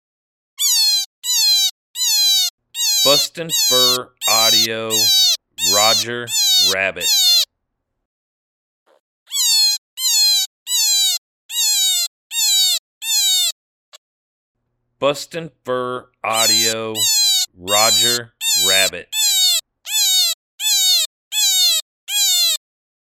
Juvenile Eastern Cottontail Rabbit in distress, great for calling all predators.